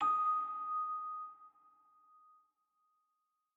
celesta1_4.ogg